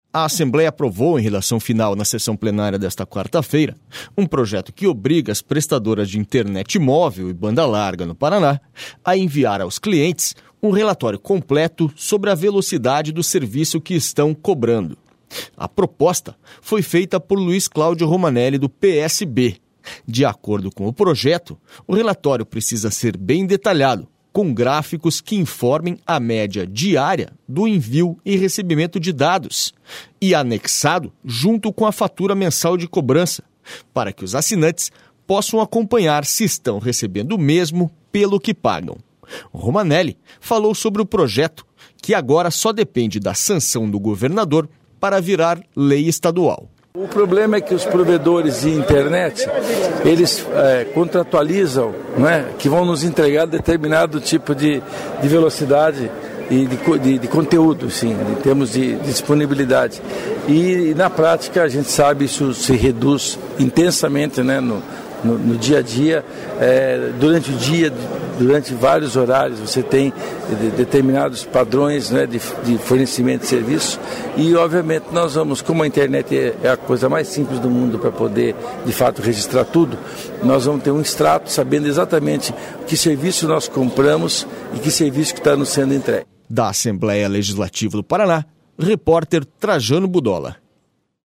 SONORA LUIZ CLAUDIO ROMANELLI